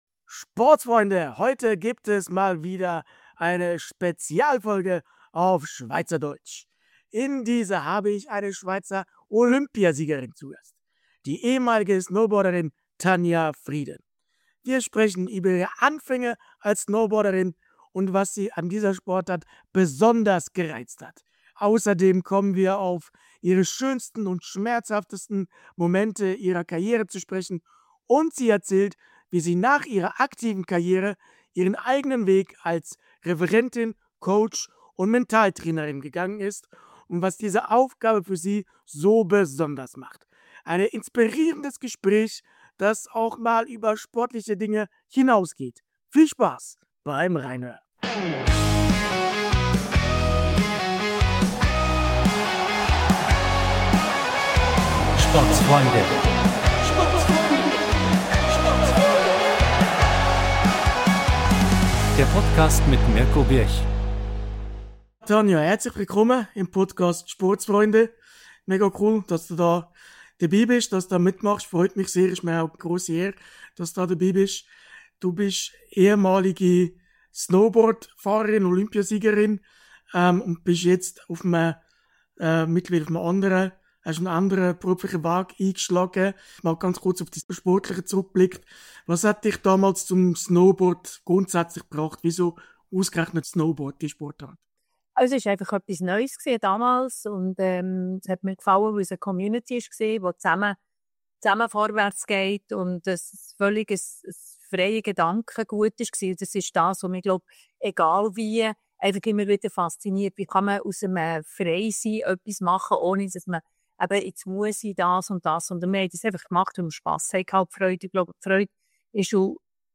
ex-snowboarderin-und-olympiasiegerin-tanja-frieden-spezialfolge-auf-schweizerdeutsch.mp3